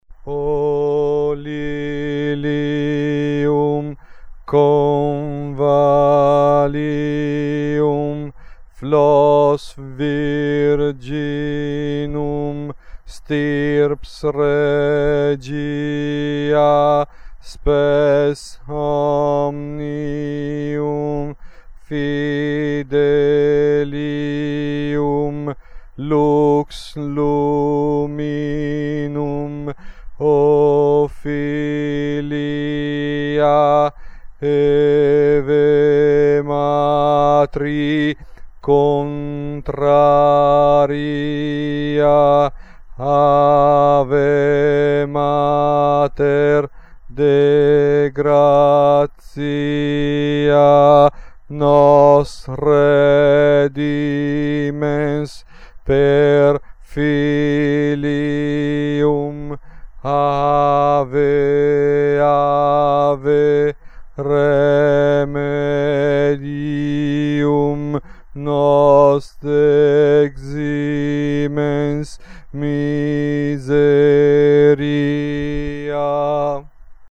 UOMINI